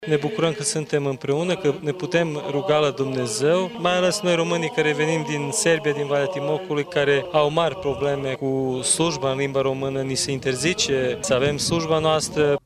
Şi românii din afara graniţelor au nevoie de o strategie coerentă pentru salvarea identităţii naţionale şi a credinţei ortodoxe, s-a subliniat la Universitatea de la Izvorul Mureşului.